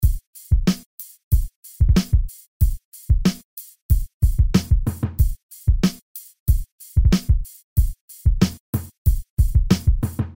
标签： 95 bpm Hip Hop Loops Bass Loops 3.40 MB wav Key : Unknown
声道立体声